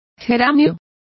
Complete with pronunciation of the translation of geranium.